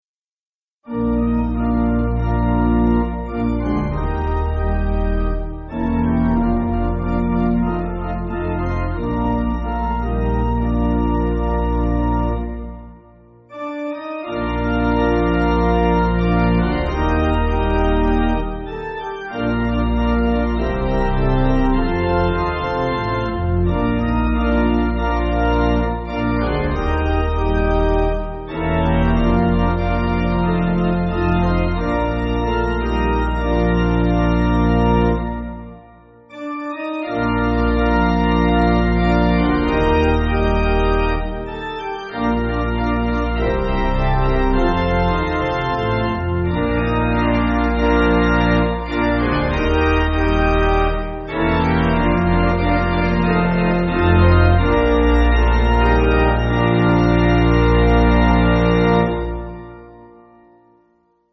Organ
(CM)   2/Bb